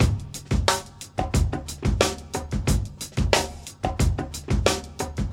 • 90 Bpm Drum Beat G# Key.wav
Free drum beat - kick tuned to the G# note. Loudest frequency: 2373Hz
90-bpm-drum-beat-g-sharp-key-9Bp.wav